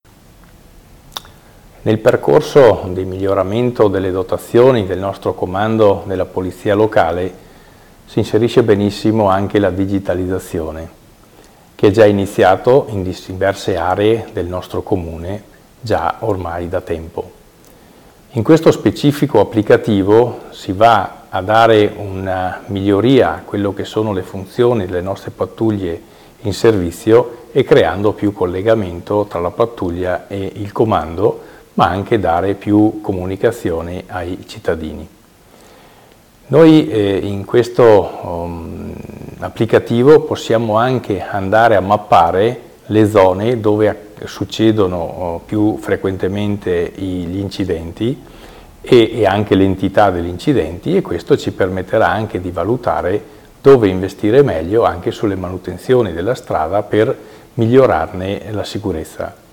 IL SINDACO DI BELLUNO OSCAR DE PELLEGRIN
oscar-de-pelletrin-intervento.mp3